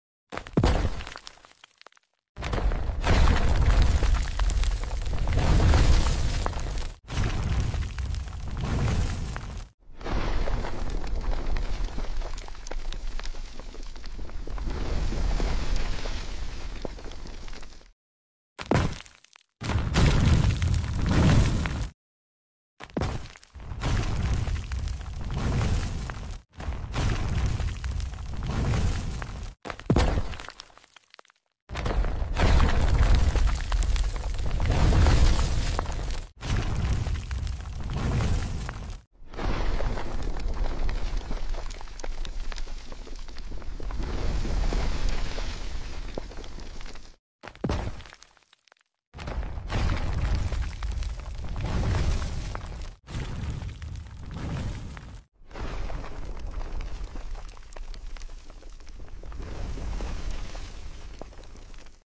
Ground Explosion Dubris Flying VFX sound effects free download